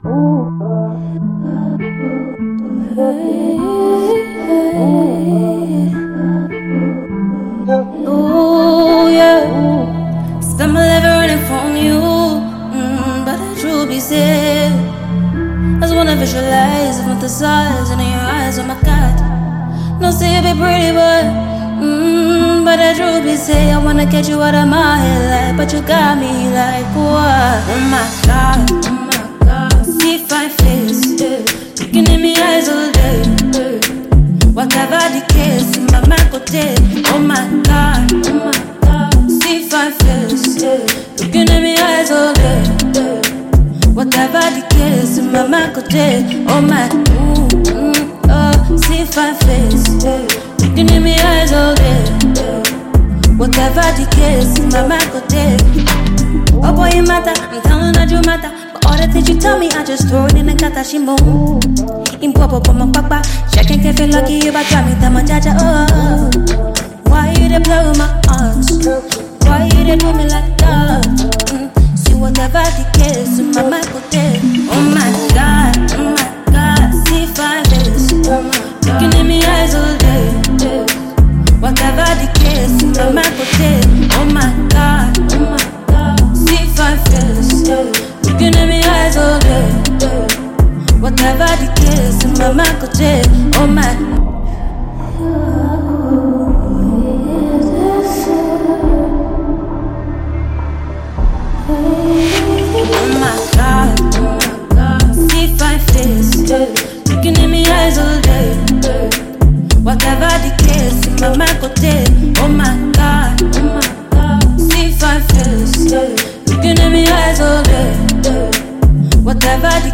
Ghana Music Music
female musician